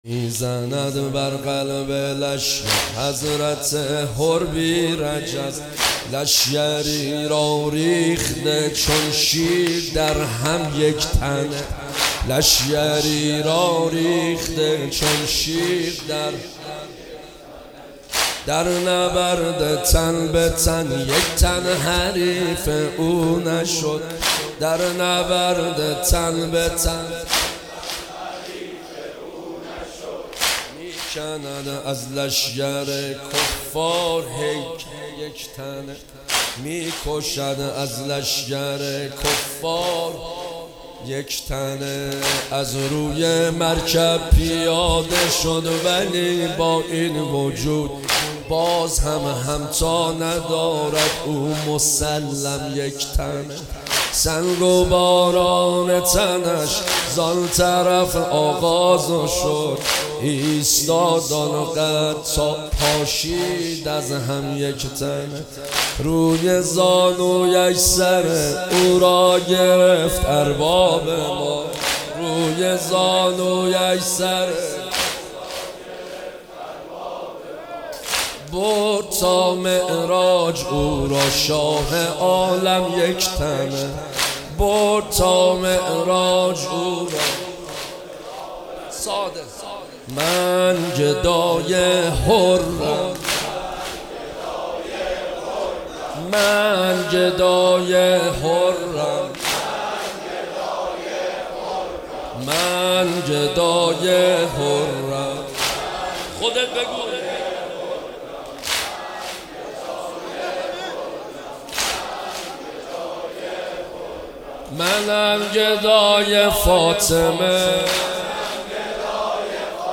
مداحی جدید
شب چهارم محرم 1400